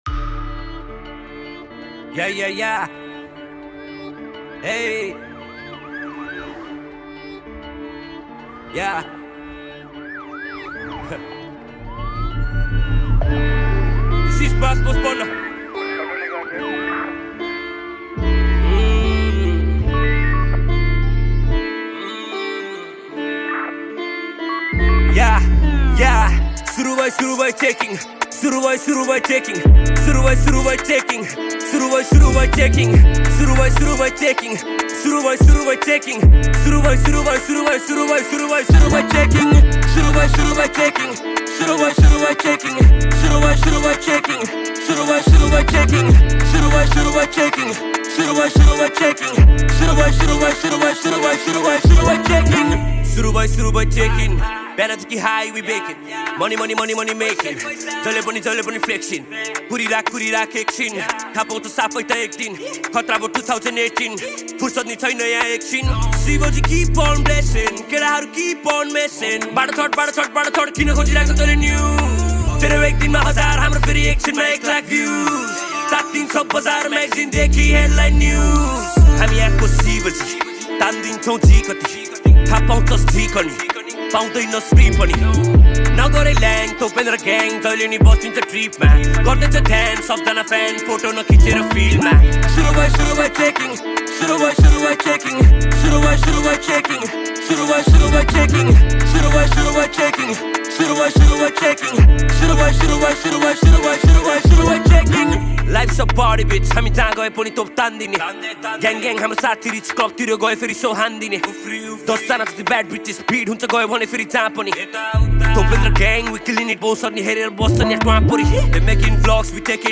# Nepali Hiphop Song